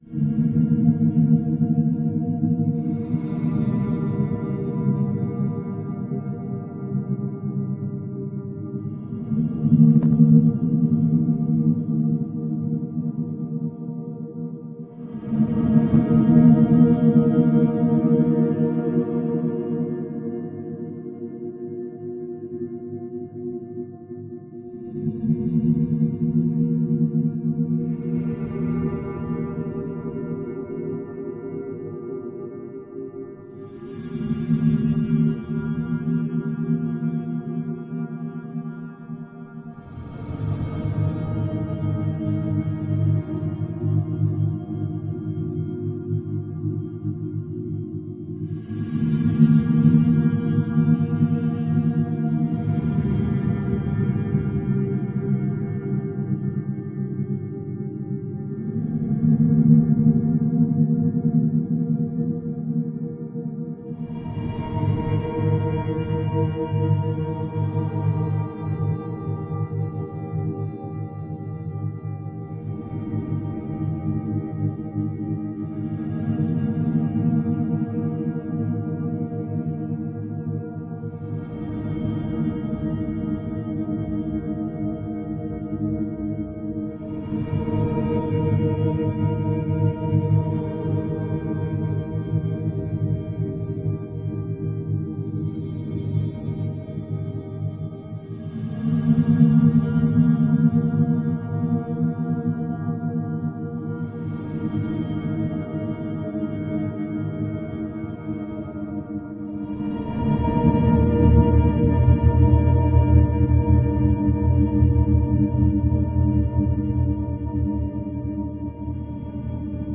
沼泽
描述：在水底像循环的声音，这是一个海滩的现场录音，有低通，所以处理了。
标签： 无人机 现场录音 水下
声道立体声